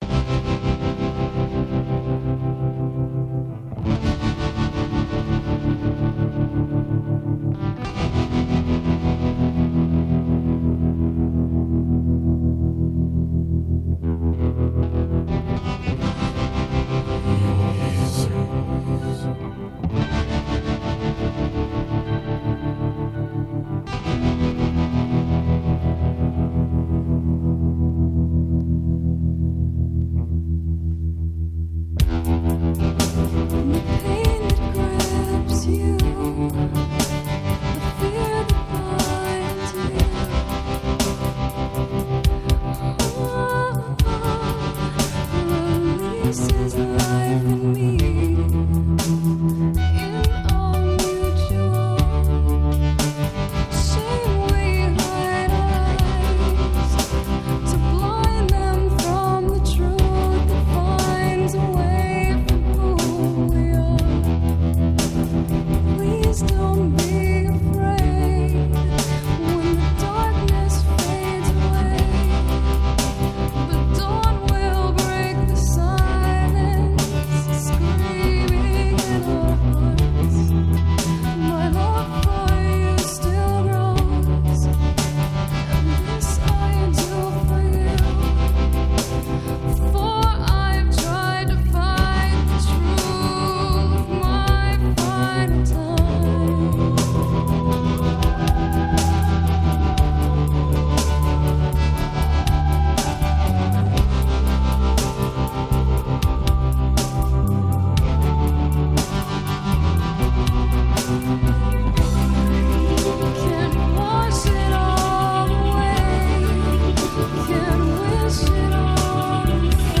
Жанр: Gothic Metal